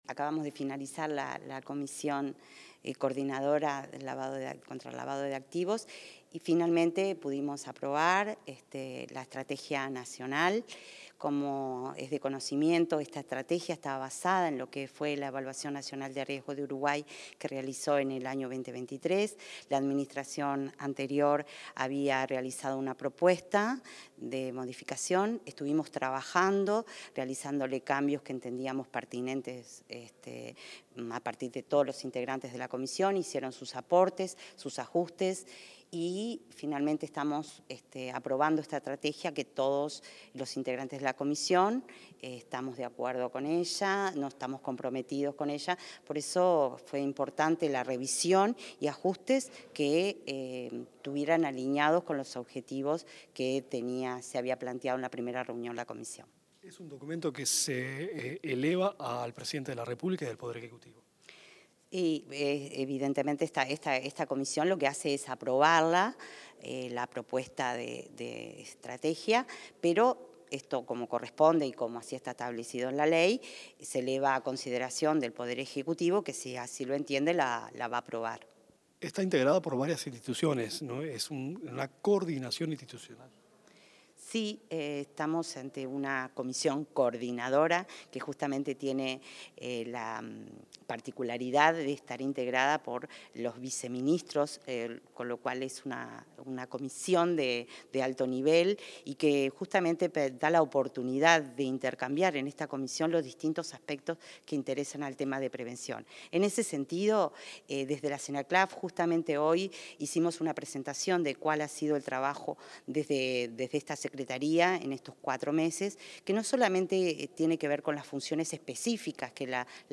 Declaraciones de la titular de la Senaclaft, Sandra Libonatti